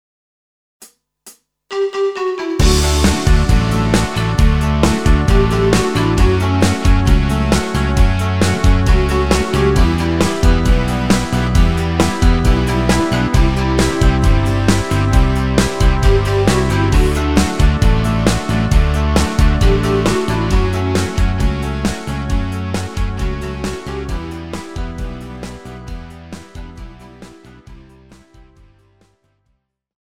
Žánr: Rock
MP3 ukázka s melo. linkou